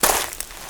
STEPS Leaves, Walk 28.wav